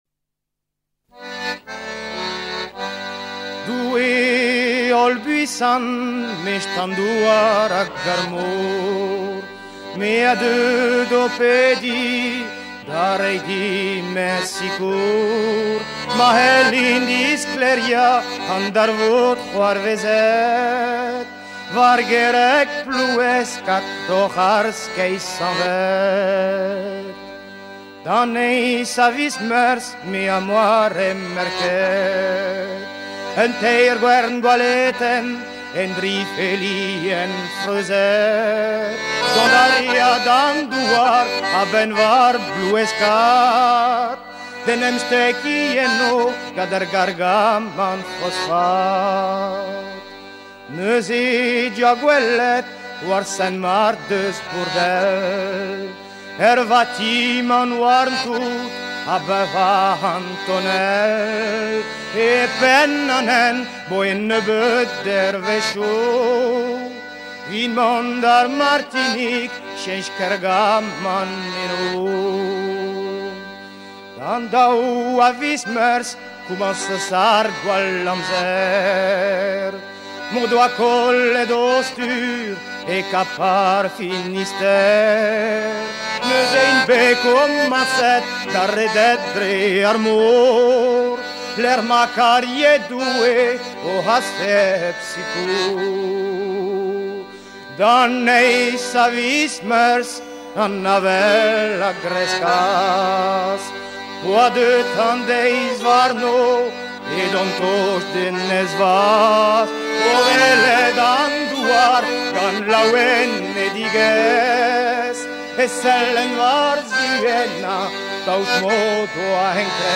Edition discographique Chants de marins traditionnels, vol. I à V